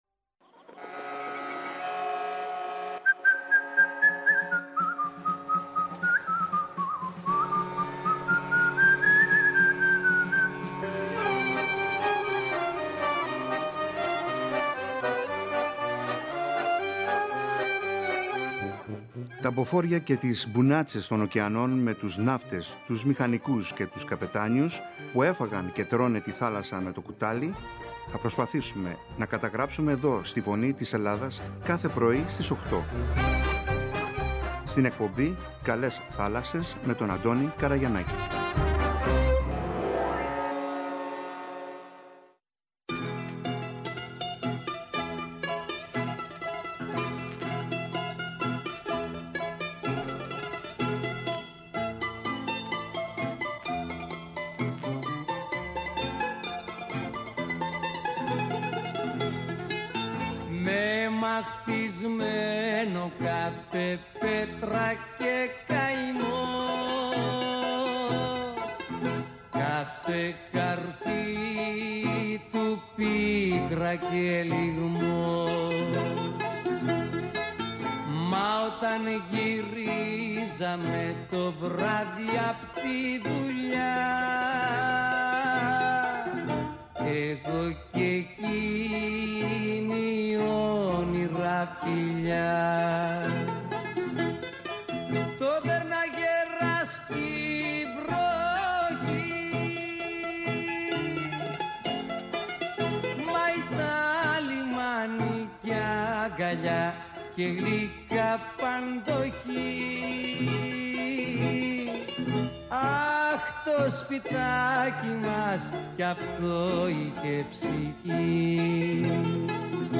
Μας διάβασε την ιστορία «Ο Μπακλαβάς».